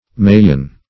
maian - definition of maian - synonyms, pronunciation, spelling from Free Dictionary Search Result for " maian" : The Collaborative International Dictionary of English v.0.48: Maian \Ma"ian\, n. (Zool.) Any spider crab of the genus Maia, or family Maiadae .